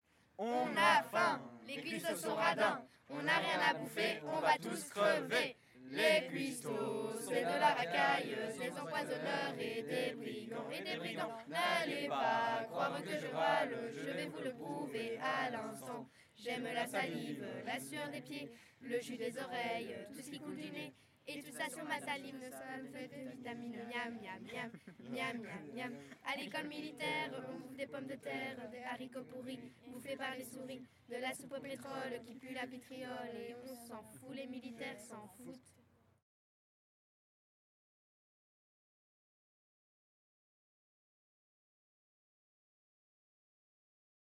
Genre : chant
Type : chant de mouvement de jeunesse
Interprète(s) : Les Scouts marins de Jambes
Lieu d'enregistrement : Jambes